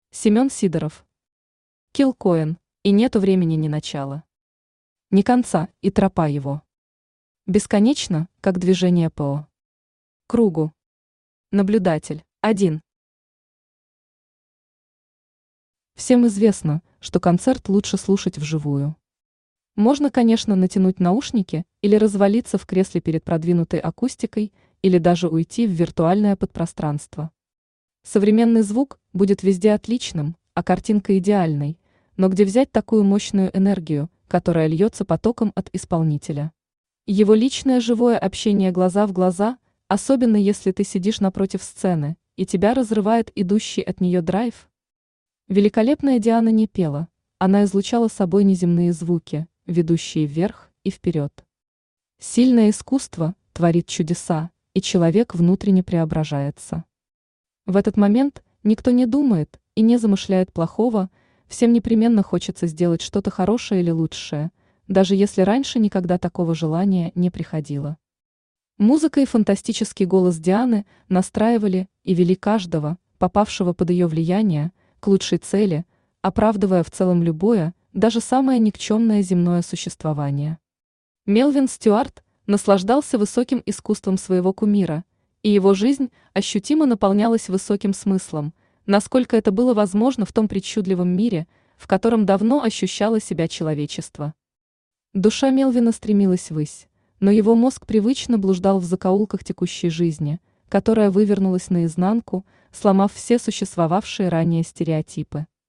Аудиокнига Киллкоин | Библиотека аудиокниг
Aудиокнига Киллкоин Автор Семен Сидоров Читает аудиокнигу Авточтец ЛитРес.